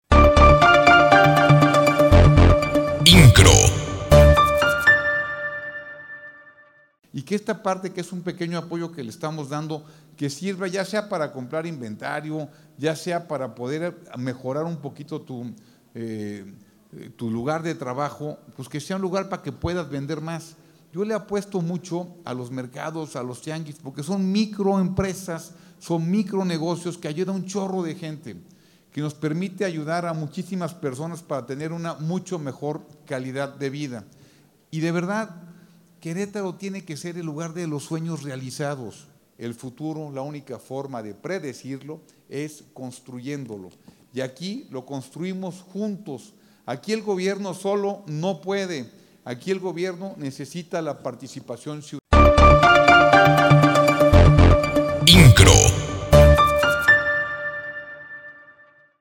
Este es un año de consolidación para Querétaro, afirmó el gobernador, Mauricio Kuri González, al presidir la entrega de apoyos económicos del programa Contigo Crecemos que beneficia a 693 personas que pertenecen al sector de tianguistas, taxistas y locatarios de mercados, con un monto único de cinco mil pesos cada uno, acción que fomenta su reactivación, crecimiento e integración social y económica.